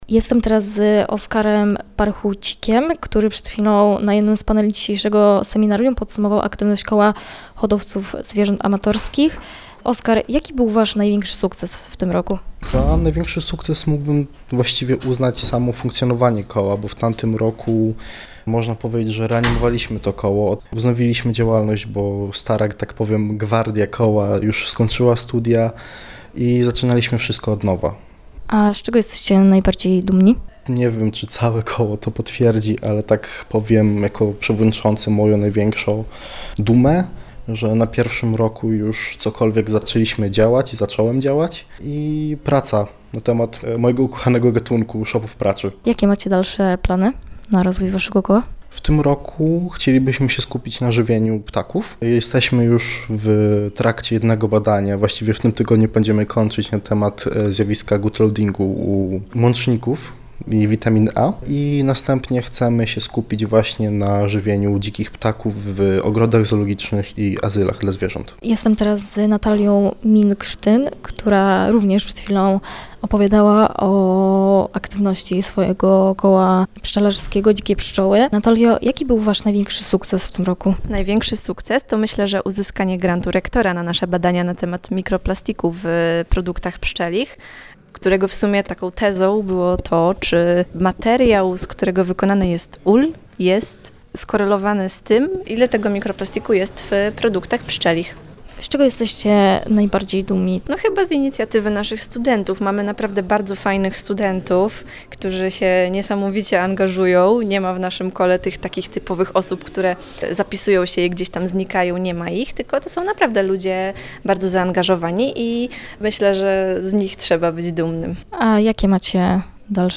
Z przedstawicielami Studenckiego Koła Naukowego Hodowców Zwierząt Amatorskich oraz Naukowego Koła Pszczelarskiego „Dzikie Pszczoły” rozmawiała nasza reporterka.